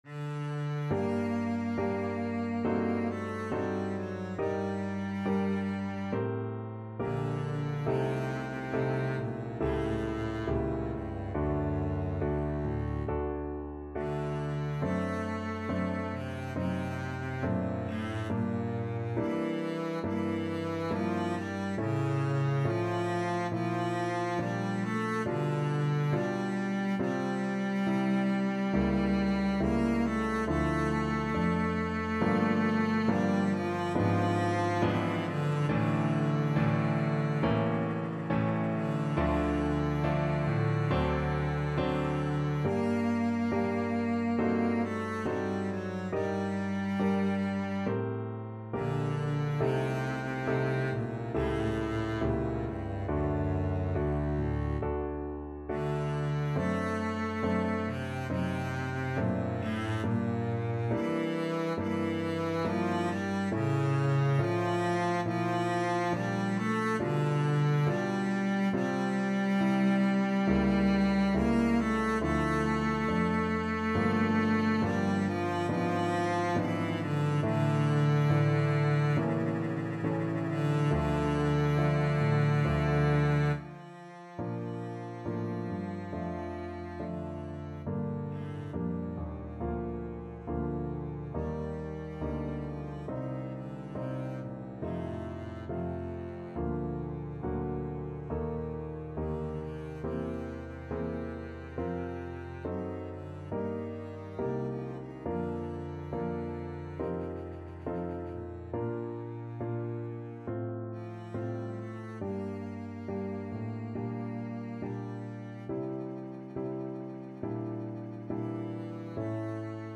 Double Bass
G minor (Sounding Pitch) (View more G minor Music for Double Bass )
~ = 100 Grave (=69)
4/4 (View more 4/4 Music)
D3-D5
Classical (View more Classical Double Bass Music)